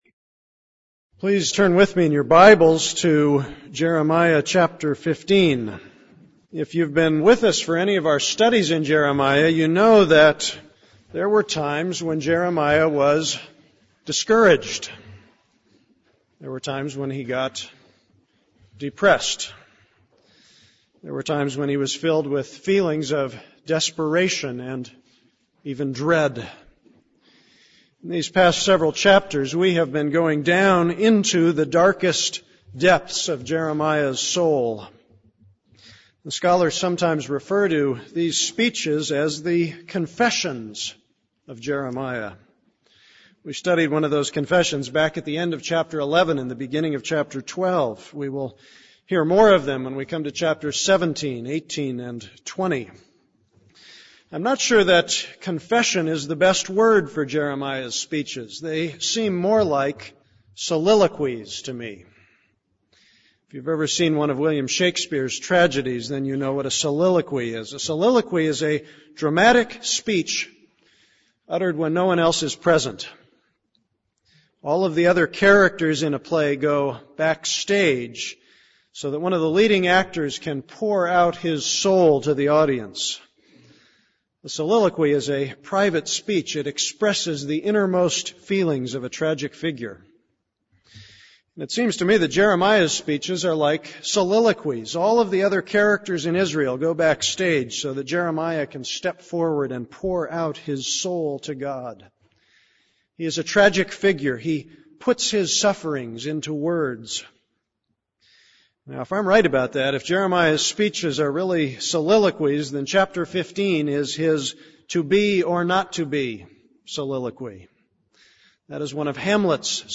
This is a sermon on Jeremiah 15:1-21.